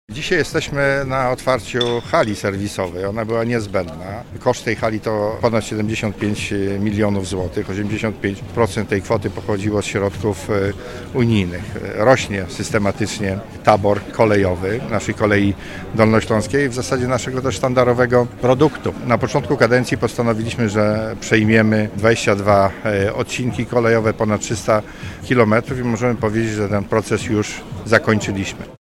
W Legnicy przy ul. Pątnowskiej uroczyście otwarto nową halę serwisową KD.
–To kluczowa dla regionu inwestycja kolejowa. – zaznacza Cezary Przybylski, Marszałek Województwa Dolnośląskiego.